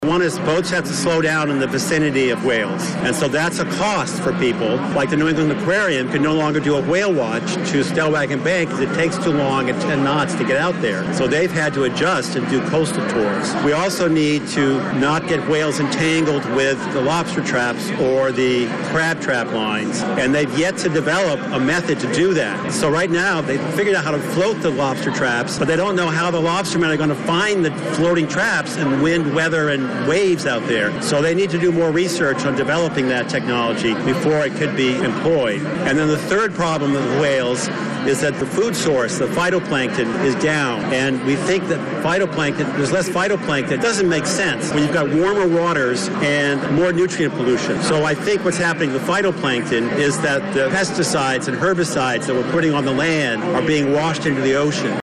observed for the first time during a ceremony at the New England Aquarium on Monday